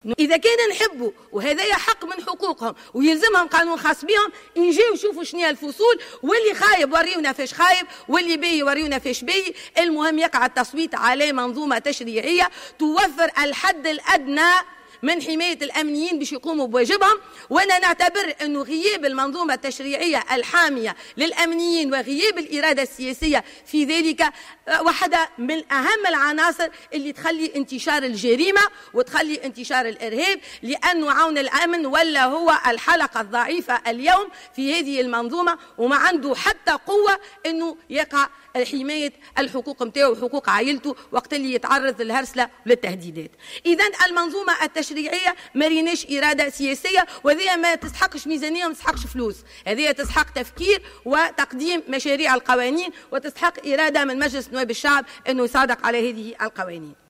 أكدت رئيسة الحزب الدستوري الحر عبير موسي في مداخلة لها في الجلسة العامة المخصصة لمناقشة ميزانية وزارة الداخلية ضرورة تكفل الدولة مدى الحياة بعائلات الشهداء الأمنيين.